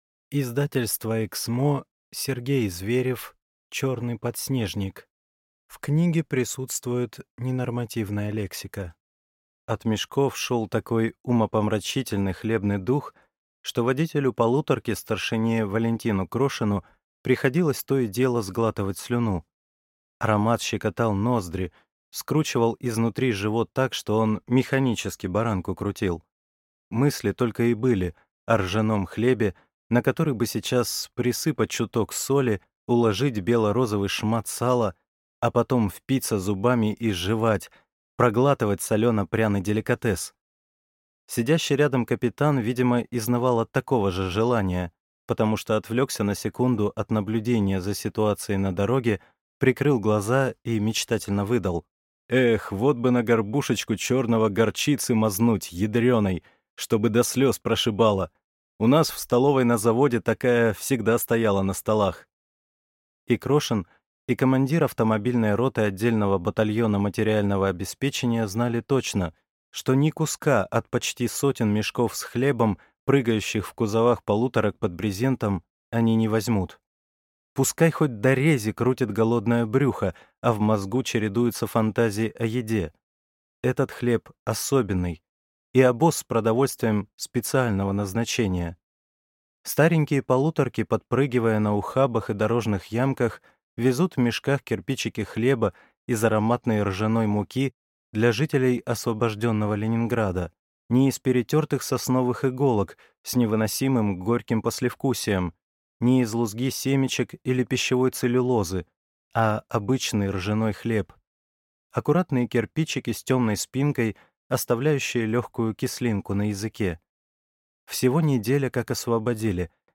Аудиокнига Черный подснежник | Библиотека аудиокниг